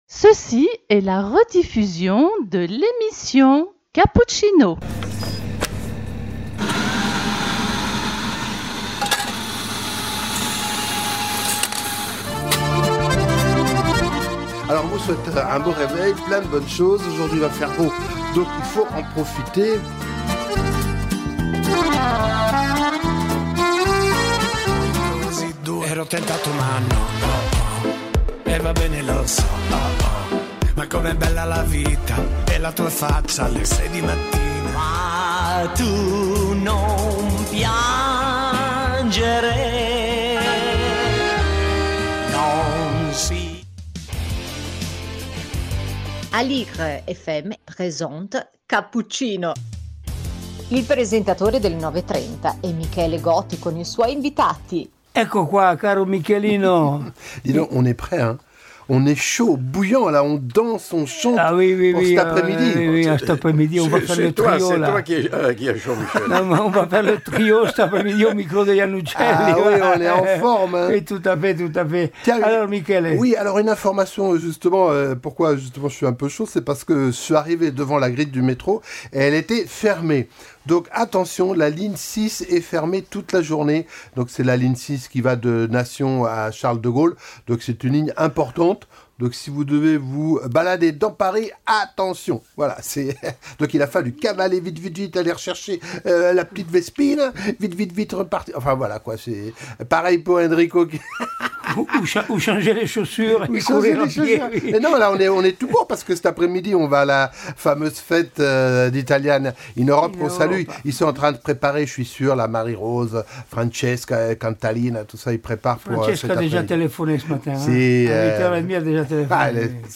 Emission avec musiques et diverses actualités culturelles et associatives.